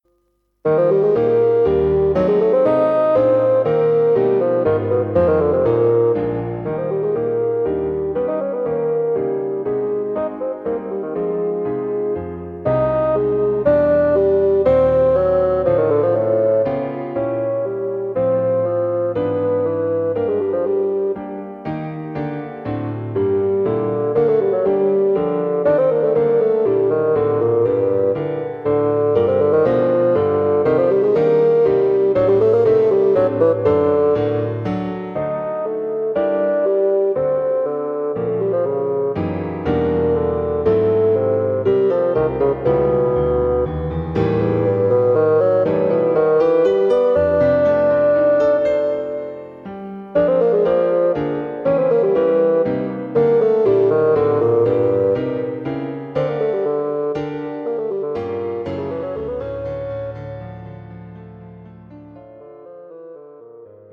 Bassoon Solo and Piano